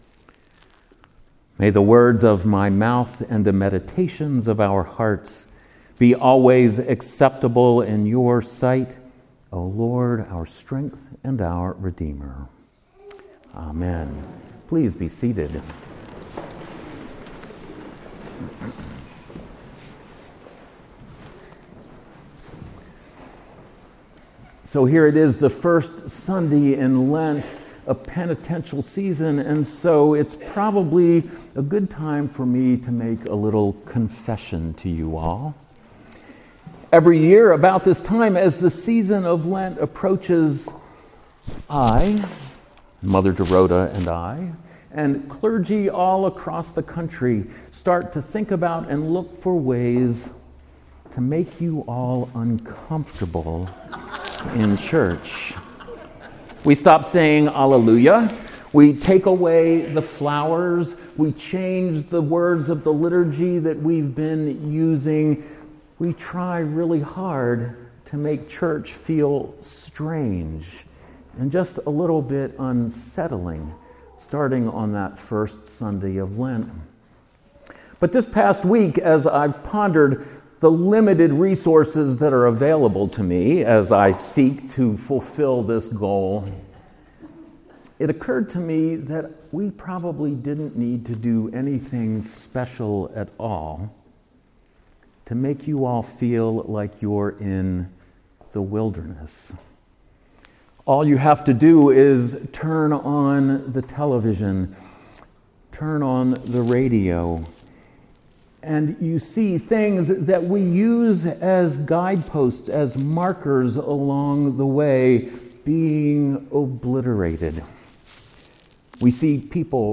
sermon-lent-1b-2018.mp3